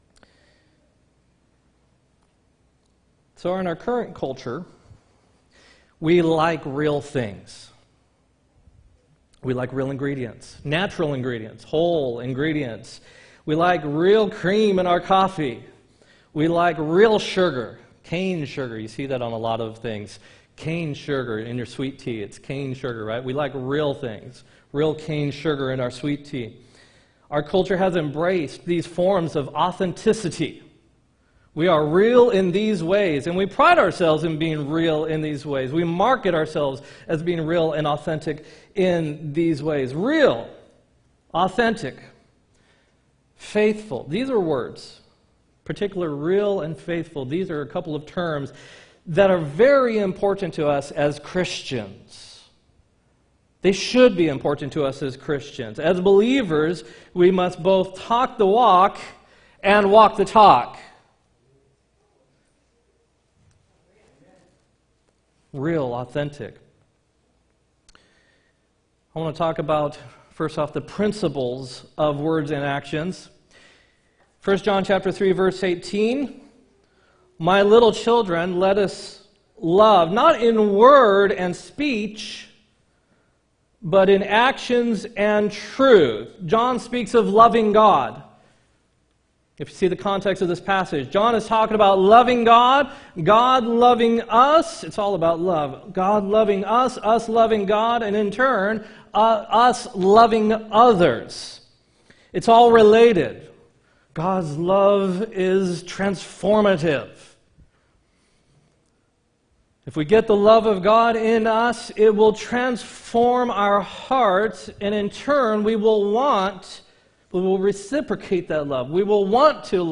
9-29-18 sermon